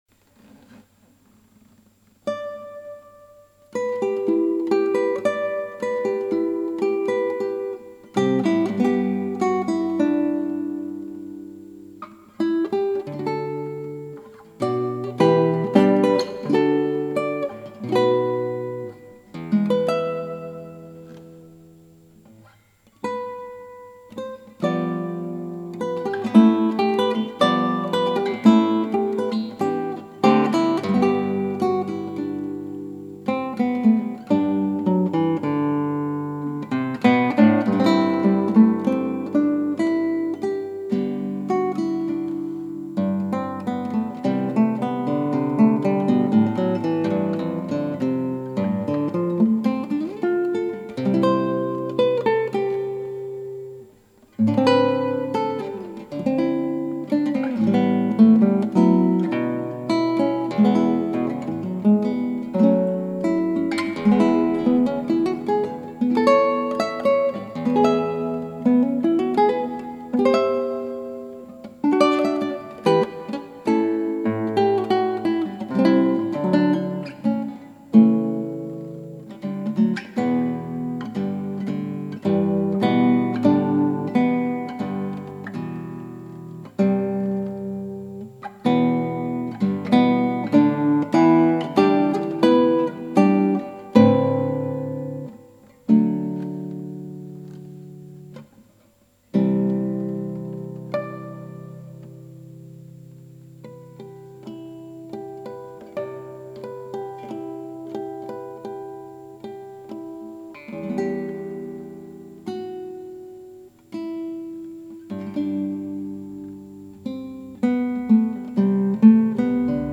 クラシックギター　ストリーミング　コンサート